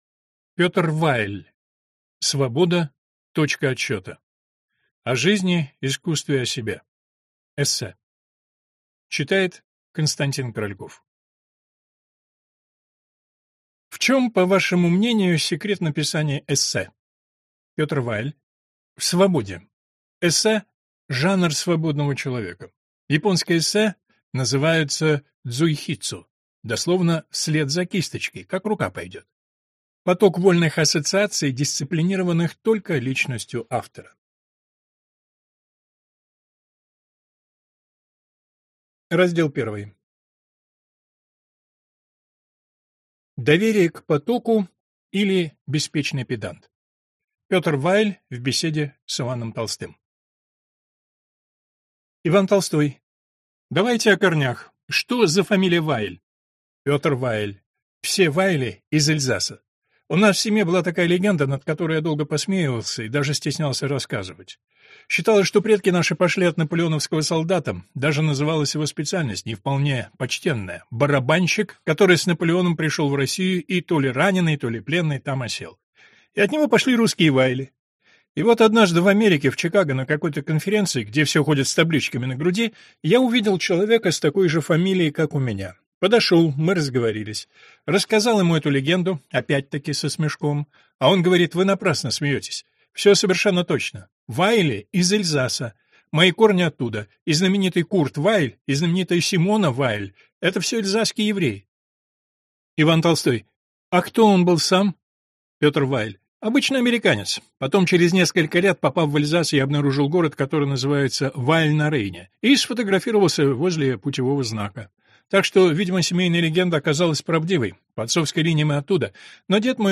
Аудиокнига Свобода – точка отсчета. О жизни, искусстве и о себе | Библиотека аудиокниг